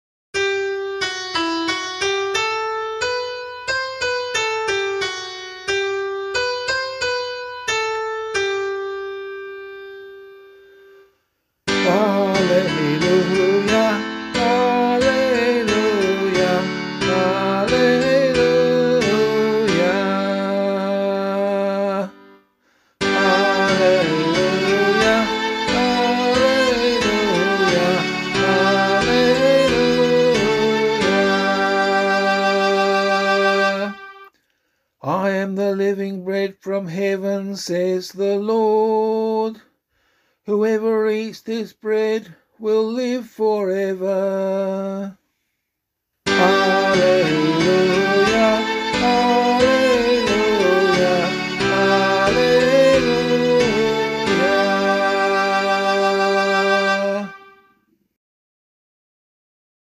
Gospel Acclamation for Australian Catholic liturgy.
vocal